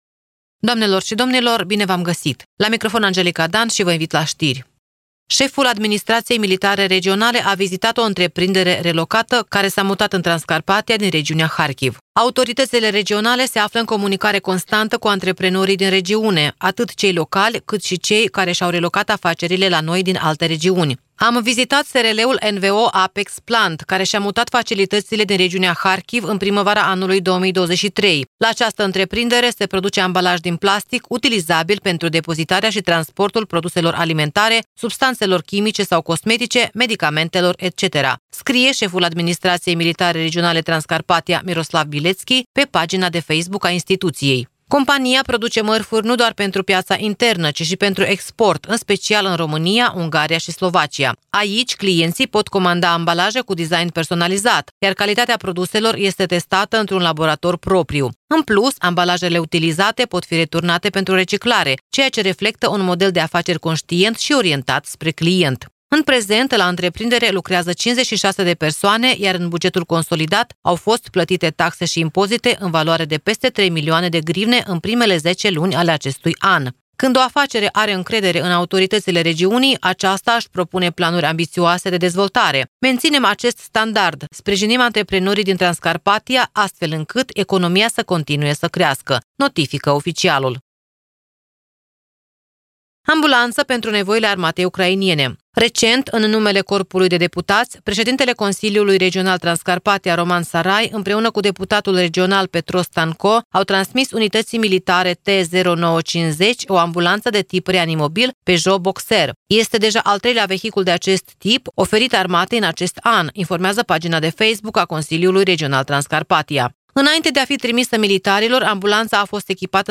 Ştiri Radio Ujgorod.